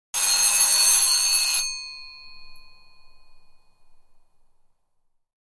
Short Analog Alarm Clock Sound Effect
Description: Short analog alarm clock sound effect. Classic twin bell retro alarm clock ringing.
Short-analog-alarm-clock-sound-effect.mp3